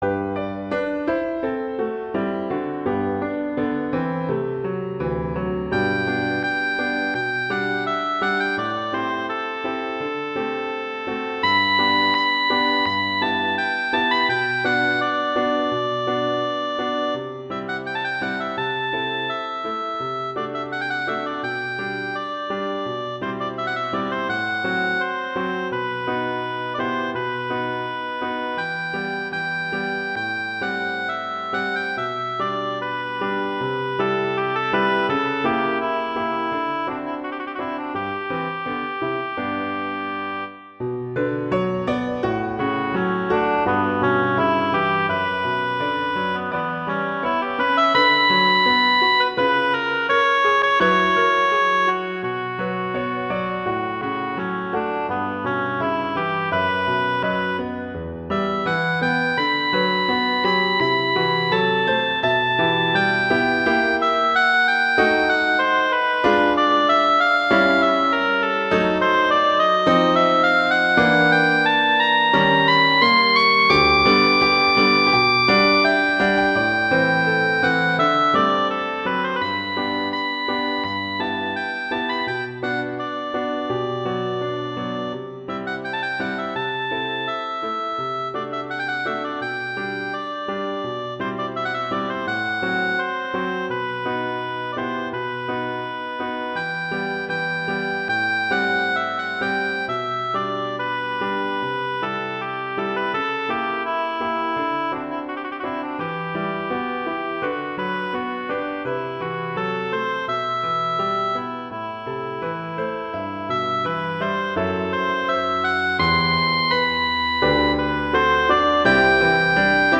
classical
G major
♩=84 BPM
Allegretto